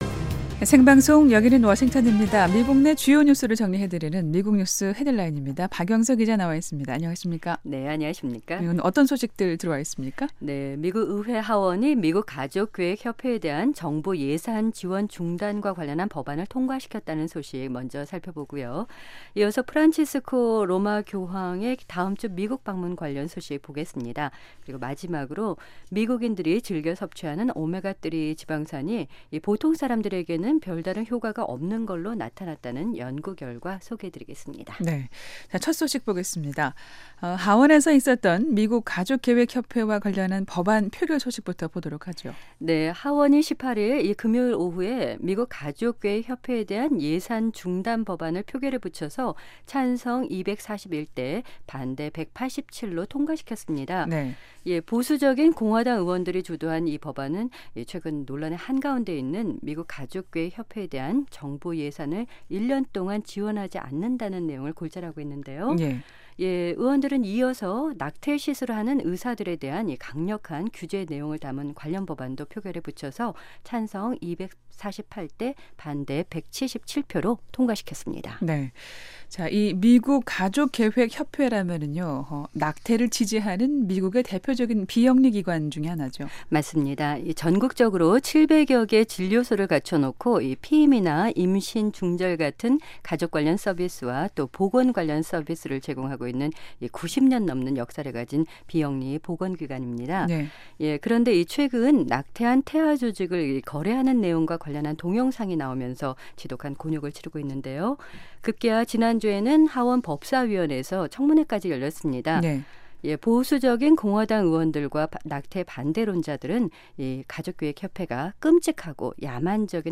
미국 내 주요 뉴스를 정리해 드리는 ‘미국 뉴스 헤드라인’입니다. 미 의회 하원이 미국가족계획협회에 대한 정부 예산 지원 중단과 관련한 법안을 통과시켰다는 소식 먼저 살펴보고요. 이어서 프란치스코 교황의 다음주 미국 방문 관련 소식 보겠습니다.